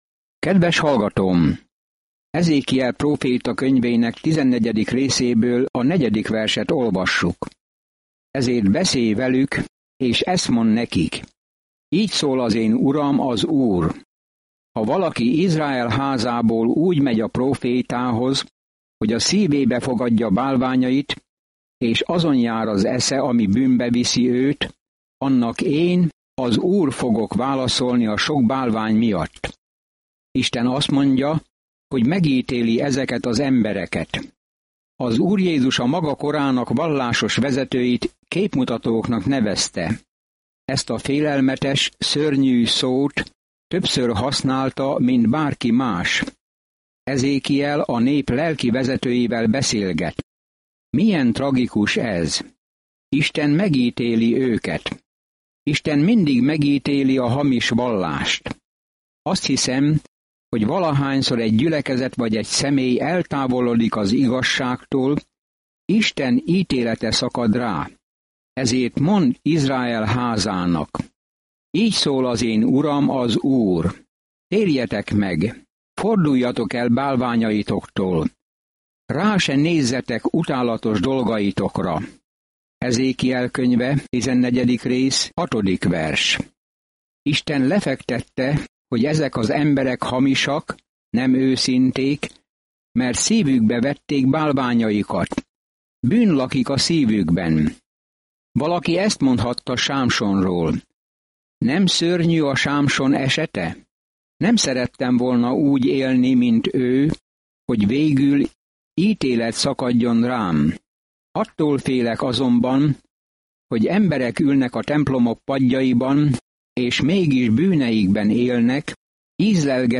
Szentírás Ezékiel 14:4-23 Ezékiel 15 Ezékiel 16:1-59 Nap 9 Olvasóterv elkezdése Nap 11 A tervről Az emberek nem hallgattak Ezékiel figyelmeztető szavaira, hogy térjenek vissza Istenhez, ezért ehelyett az apokaliptikus példázatokat adta elő, és ez meghasította az emberek szívét. Napi utazás Ezékielben, miközben hallgatja a hangos tanulmányt, és olvassa kiválasztott verseket Isten szavából.